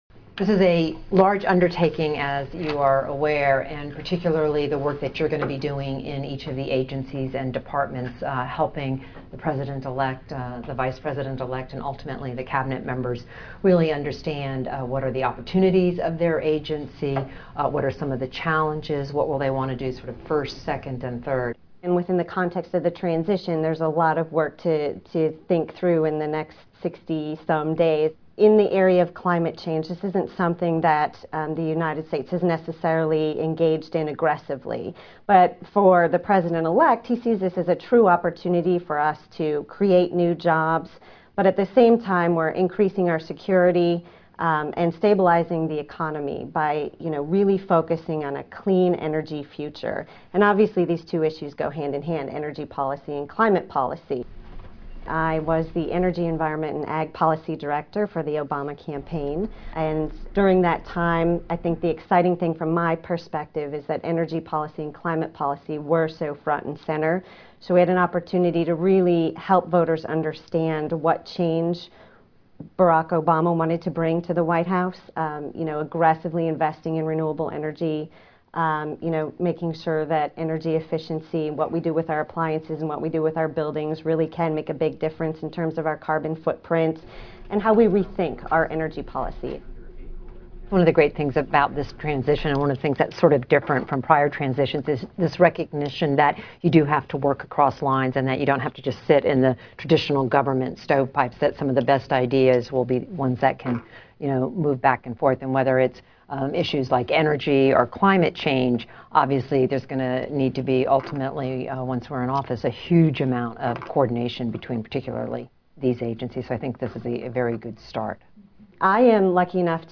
Tags: Barack Obama President Obama Barack Obama clips President Obama address Weekly Address From President Obama